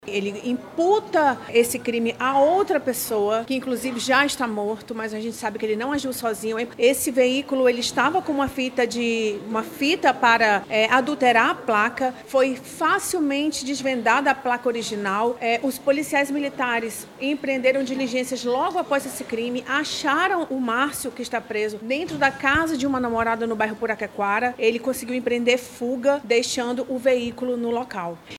Em coletiva de imprensa realizada nesta quarta-feira (19)